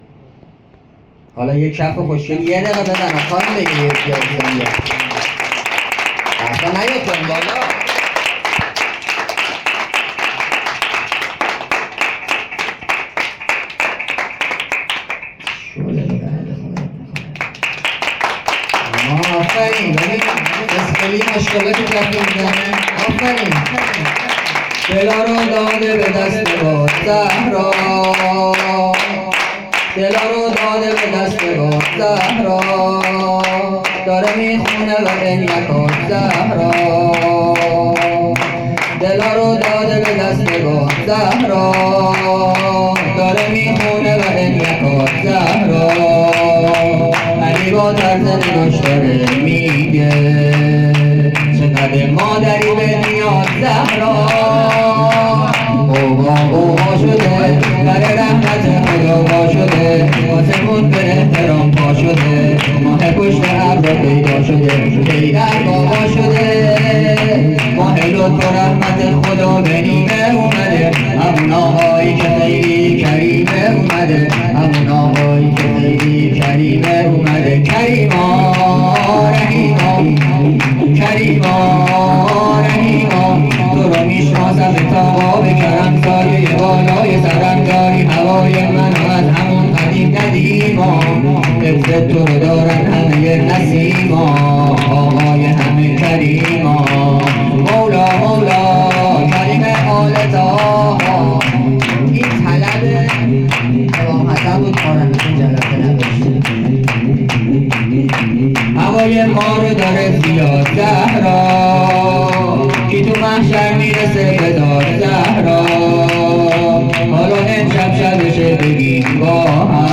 ولادت امام حسن مجتبی(ع)